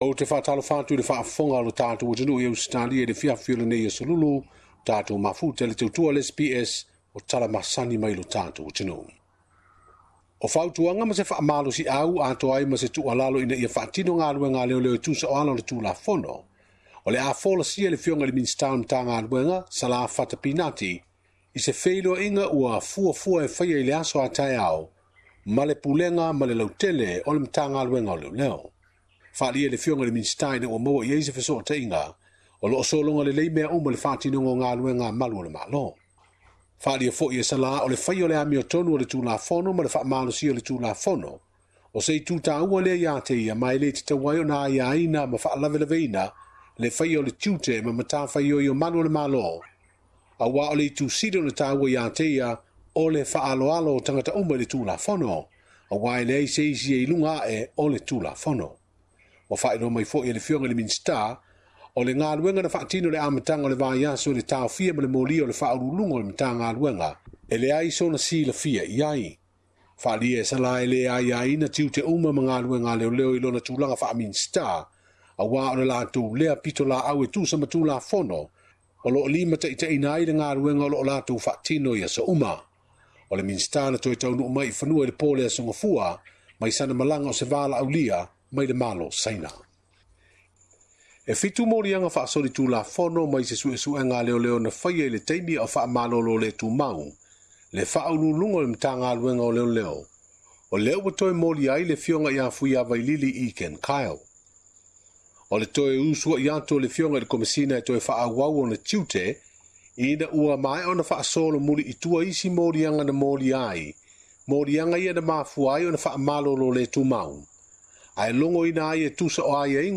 Faafofoga i se lipoti auiliili